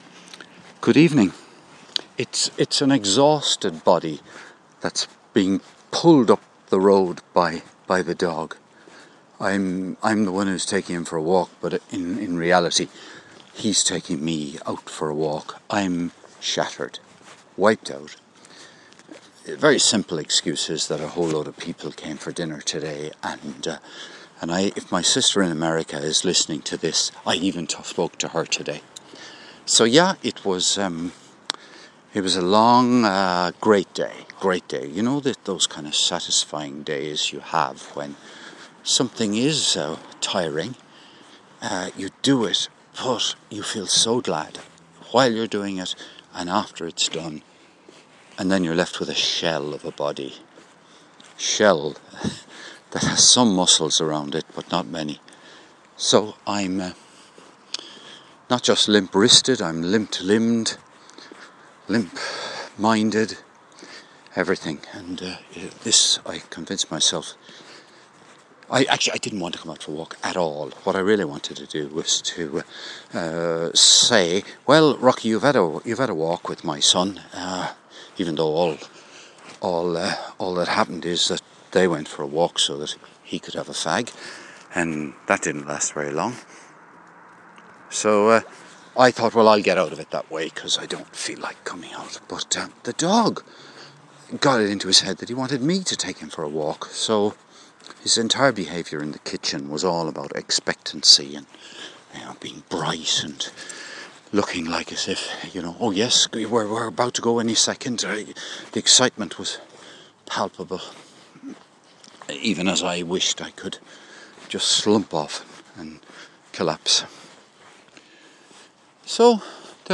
Walking the dog on Sunday night after the guests have gone home